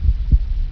heartbeat_noloop.wav